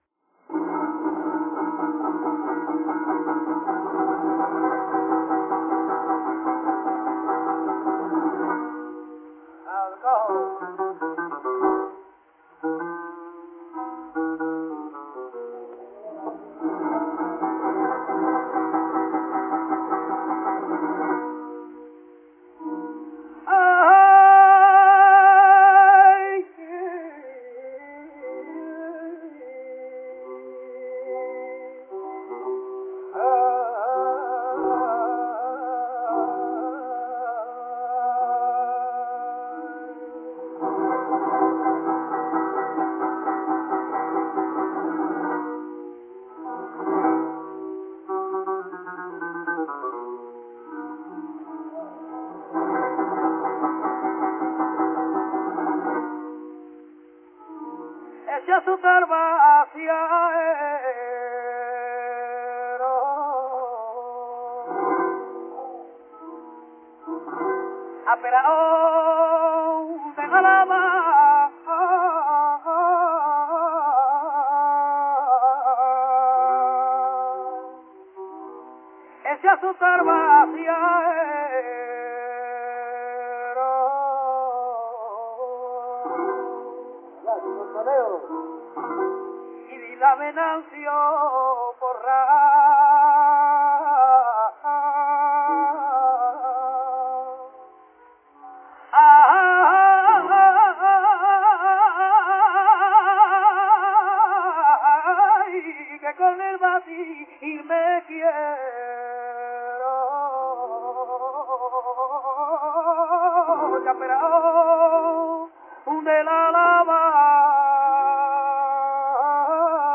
Surtout, son style vocal singulier rend ses interprétations fondamentalement originales, quel que soit le modèle de référence : sur le plan mélodique, un usage intensif des notes de passage chromatiques, souvent accompagnées de portamentos ; sur le plan ornemental, un continuum vibrato élargi/mélismes, le passage de l’un aux autres étant souvent indiscernable — de plus, contrairement à l’usage, ses mélismes plongent fréquemment sous la note porteuse, et procèdent parfois par notes disjointes, tempérées ou non.
Murciana
NB : la murciana est suivie du fandango de Lucena n° 2 (cf. deuxième partie).